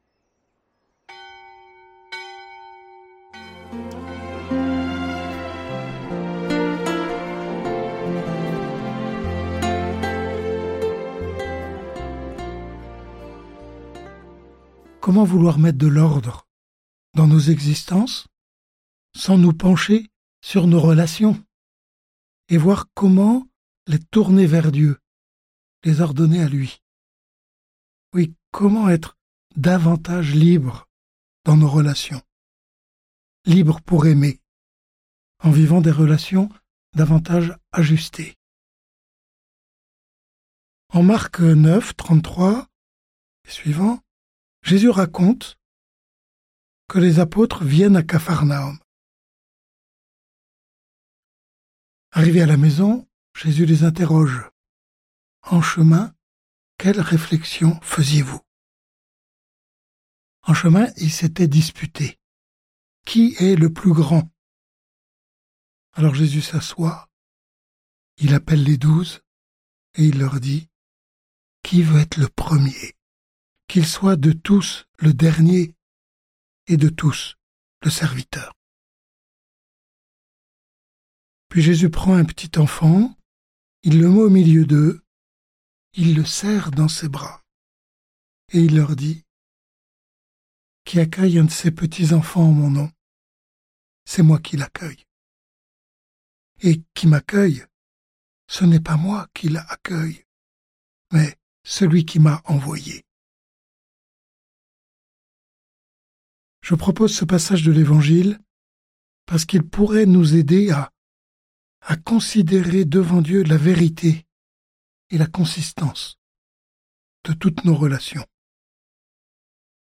Méditation guidée suivant un livre biblique, une encyclique, des psaumes, ou un thème de la vie chrétienne.